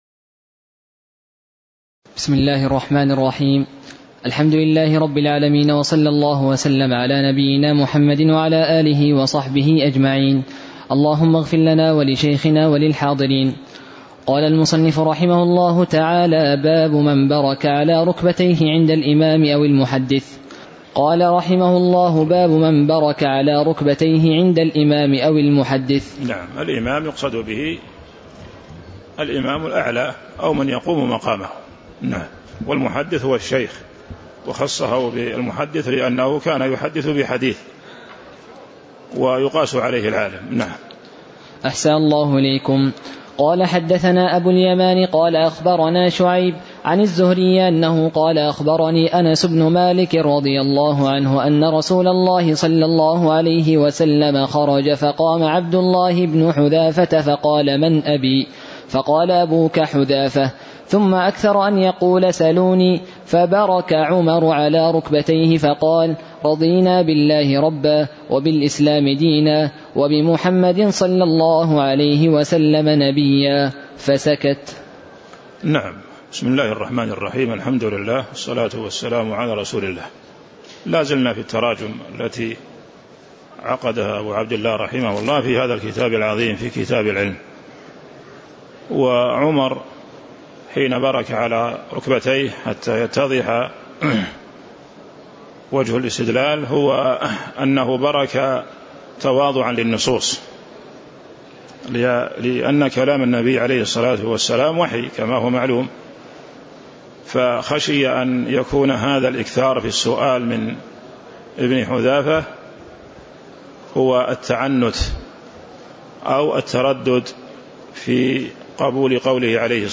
تاريخ النشر ١٣ ربيع الثاني ١٤٤٣ هـ المكان: المسجد النبوي الشيخ